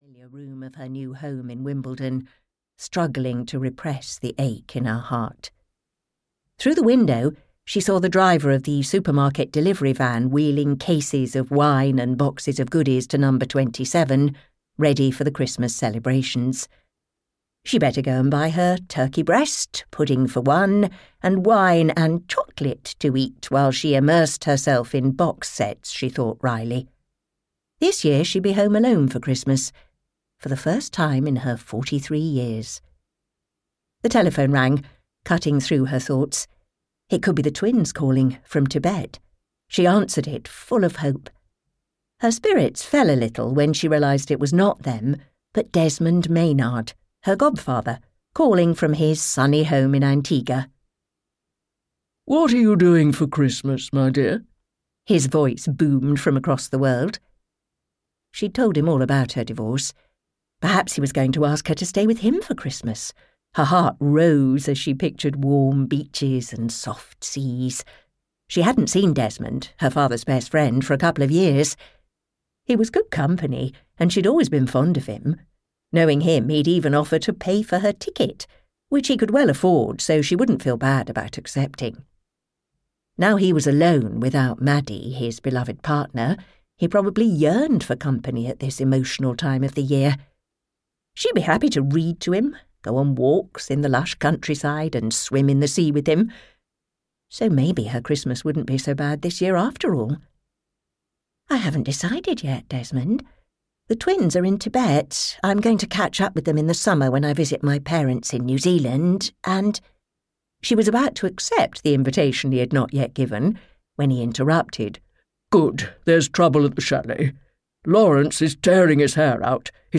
A Winter Affair (EN) audiokniha
Ukázka z knihy